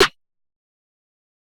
Since Way Back Snare 1.wav